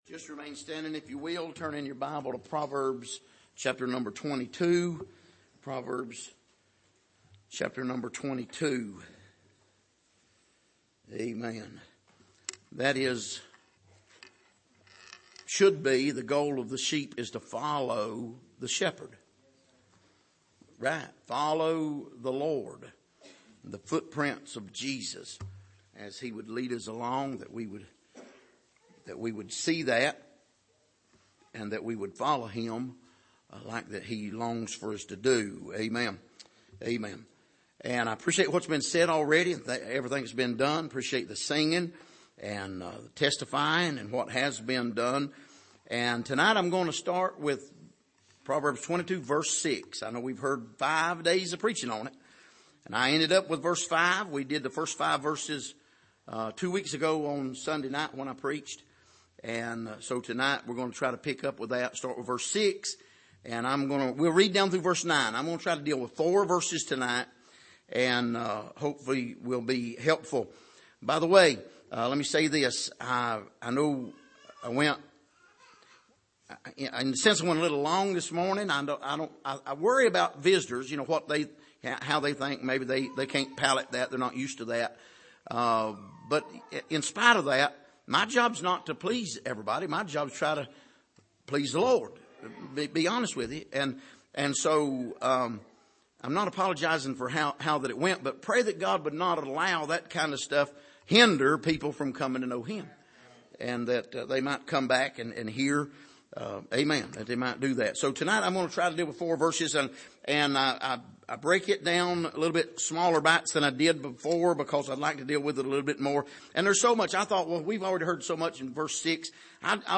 Passage: Proverbs 22:6-9 Service: Sunday Evening